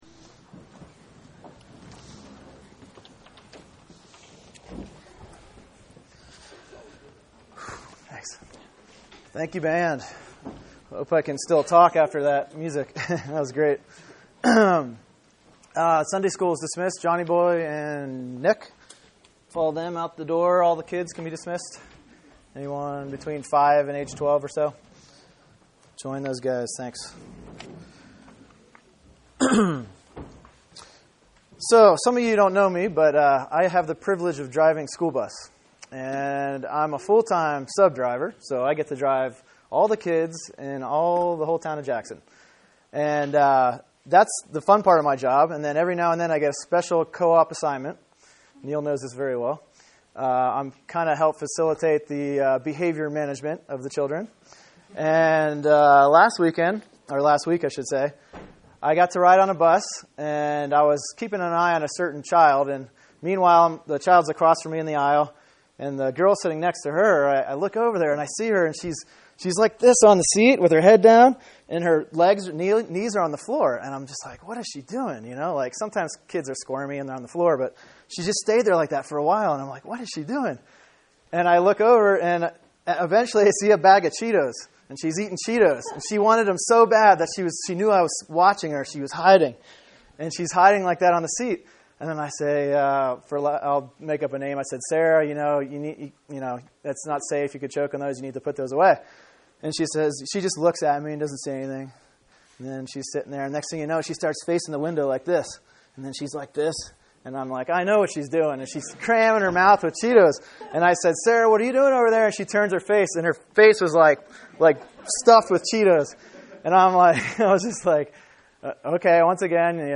Sermon: Colossians 3:5-10 “Putting Sin to Death” | Cornerstone Church - Jackson Hole